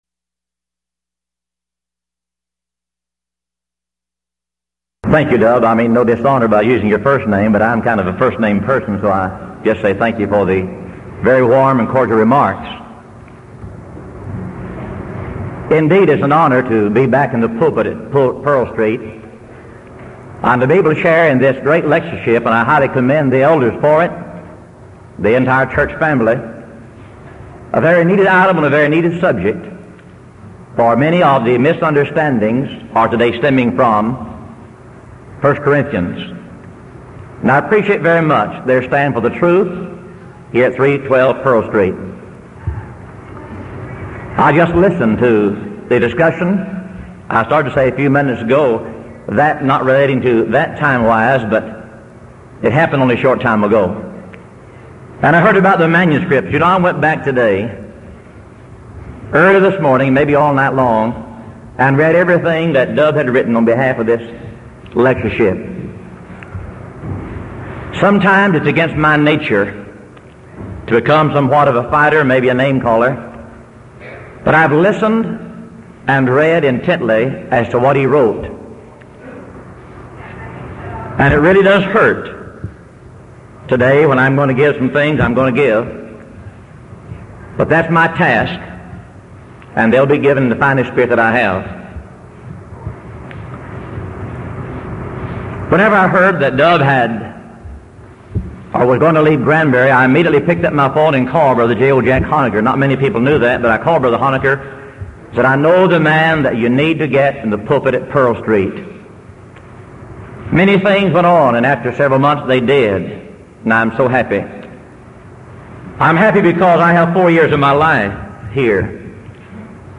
Event: 1982 Denton Lectures
lecture